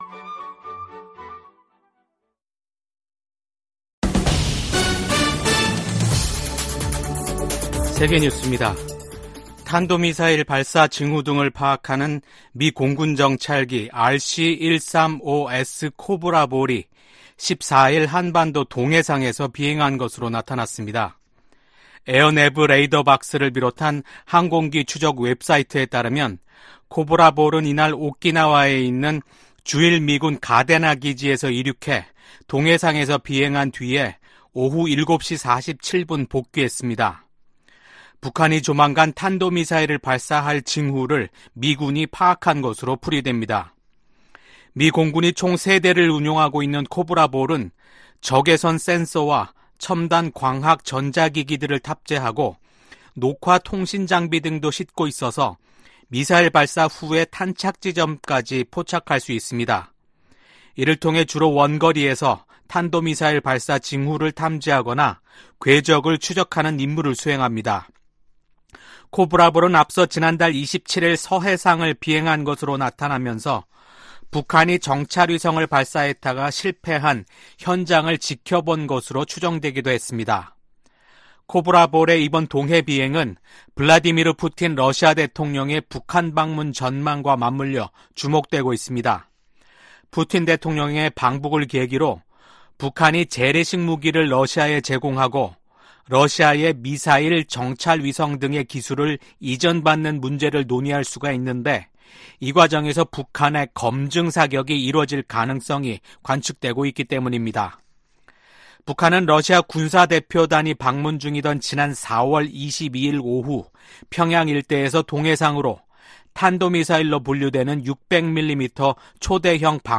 VOA 한국어 아침 뉴스 프로그램 '워싱턴 뉴스 광장' 2024년 6월 15일 방송입니다. 블라디미르 푸틴 러시아 대통령의 방북 임박설 속에 김일성 광장에 ‘무대’ 추정 대형 구조물이 등장했습니다. 미국의 전문가들은 푸틴 러시아 대통령의 방북이 동북아시아의 안보 지형을 바꿀 수도 있다고 진단했습니다. 미국 정부가 시행하는 대북 제재의 근거가 되는 ‘국가비상사태’가 또다시 1년 연장됐습니다.